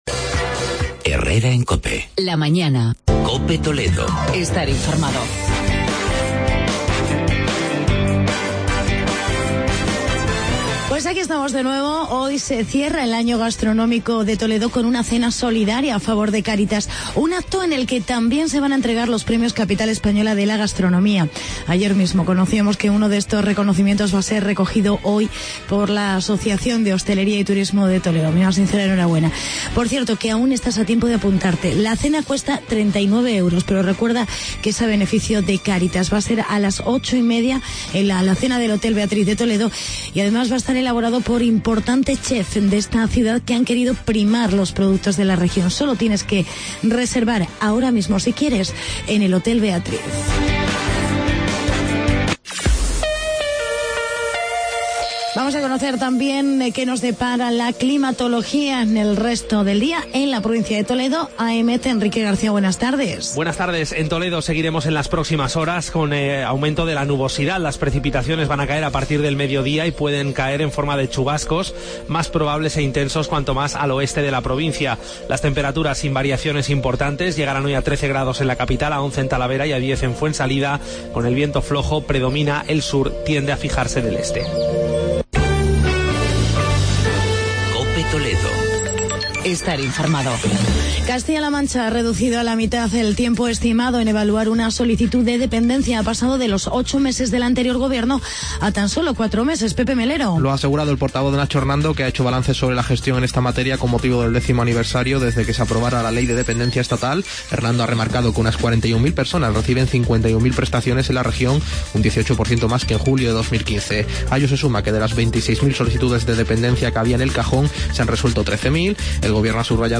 Actualidad y reportaje sobre el ahorro del agua.